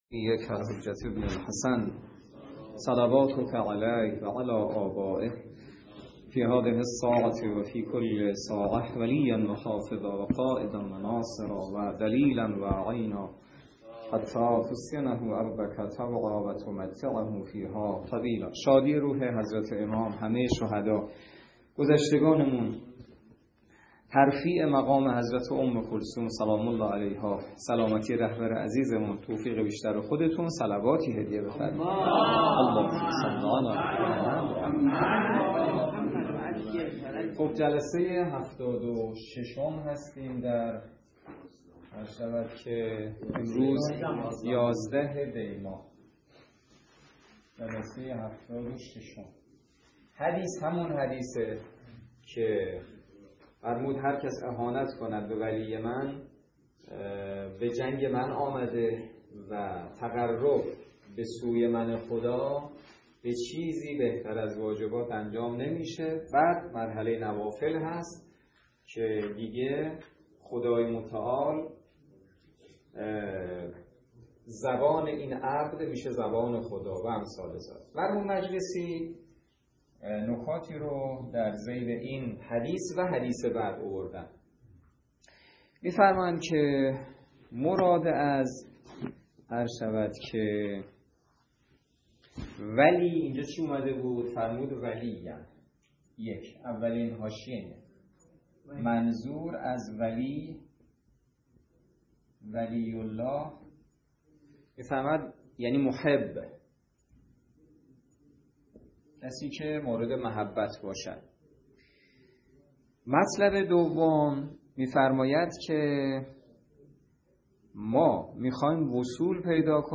درس فقه الاجاره نماینده مقام معظم رهبری در منطقه و امام جمعه کاشان - سال سوم جلسه هفتاد و شش